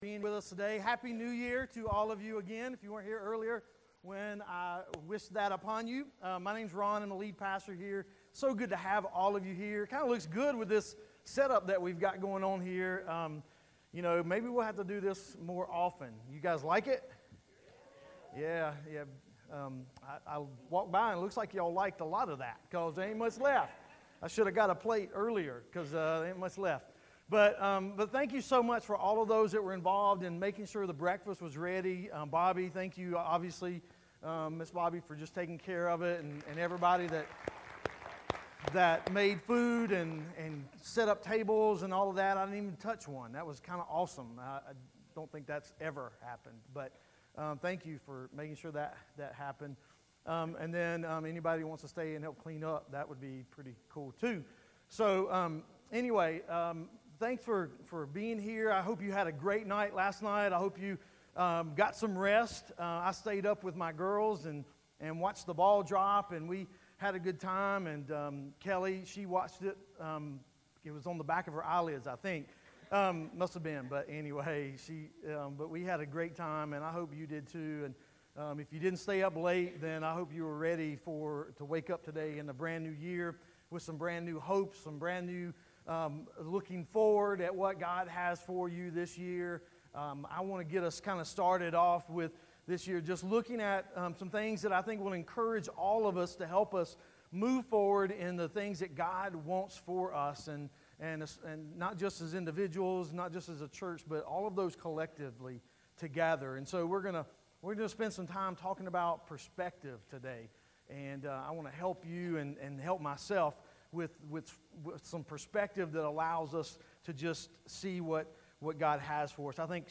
*We did experience technical issues during the recording so there is a portion missing.